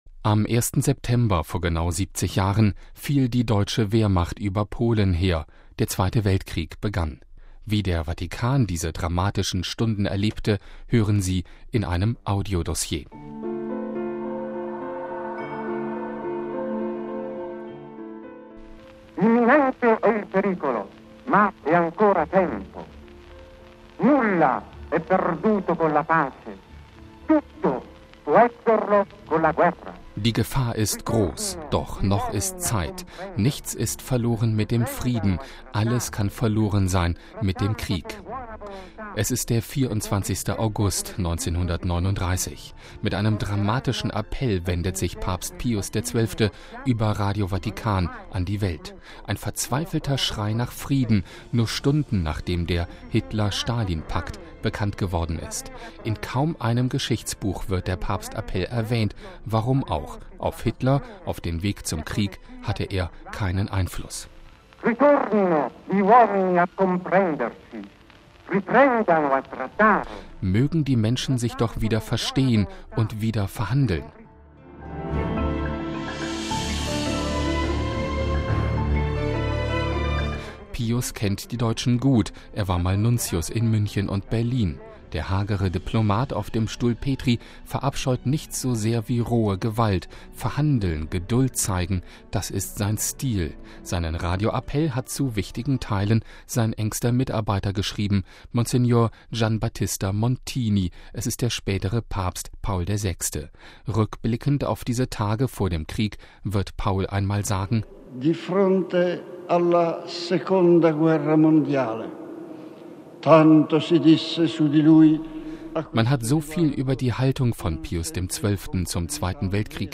Der Vatikan und der Kriegsausbruch - ein Audio-Dossier